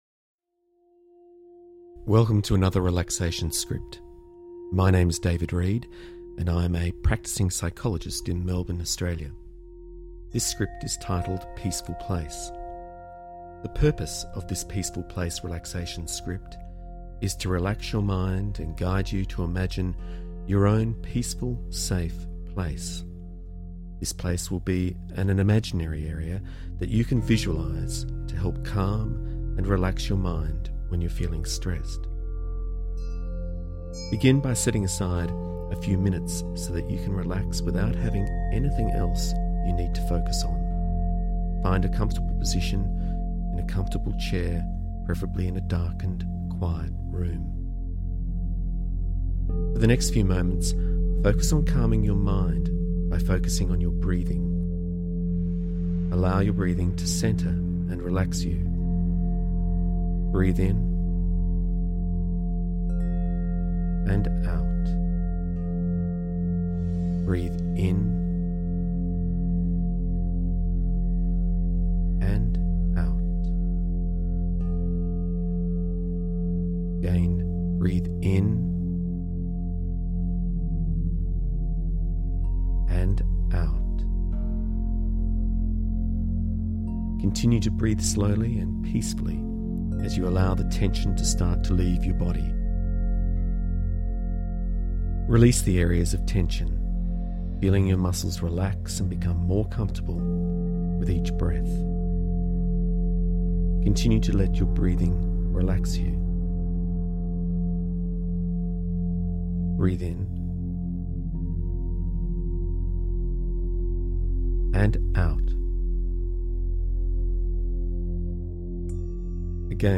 Download Guided Meditation